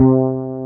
BAS.FRETC3-L.wav